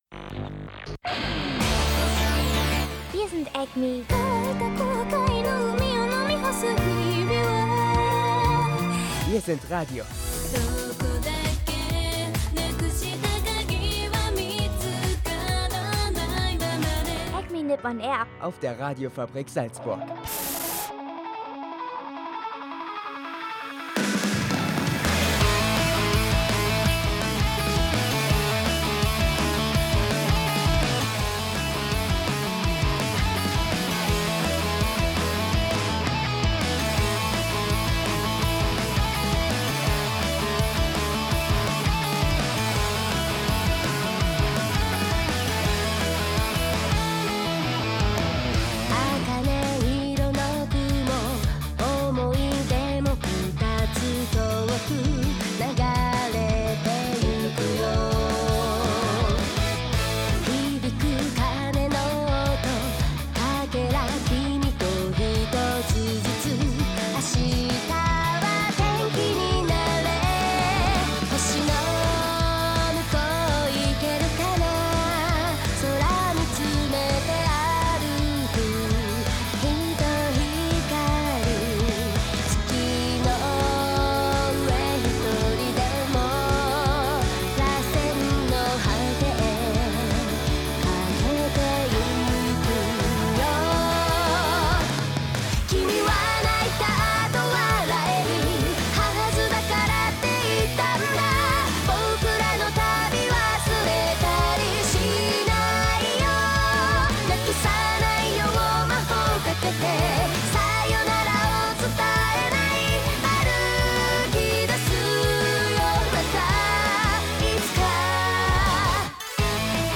Diese Woche gibt es viel Musik und dazu aktuelle News über die AnimagiC Is It Wrong to Try to Pick Up Girls in a Dungeon Japan-News und einen Animetipp für alle Synchronsprecher-Fans.